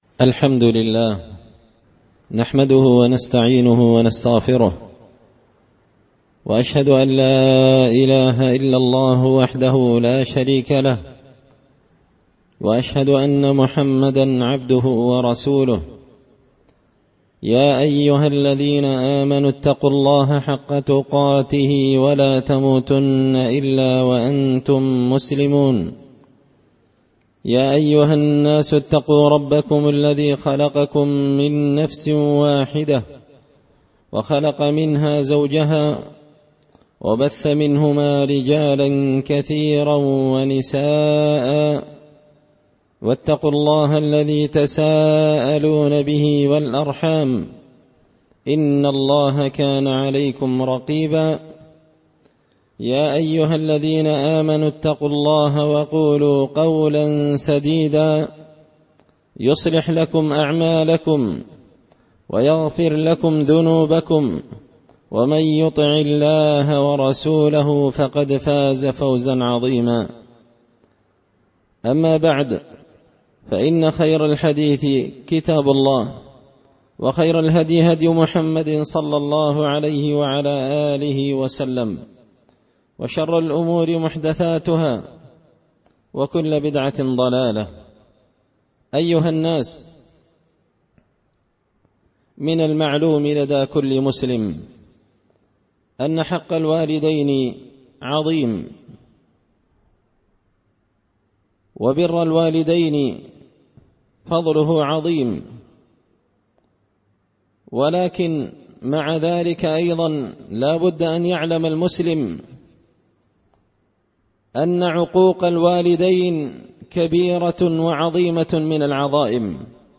خطبة جمعة بعنوان – حق الوالدين الجزء الثاني
دار الحديث بمسجد الفرقان ـ قشن ـ المهرة ـ اليمن